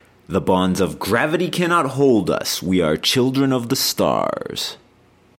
描述：与科幻相关的口头文本样本。
Tag: 语音 英语 科幻 美国航空航天局 电火花 声乐 口语 空间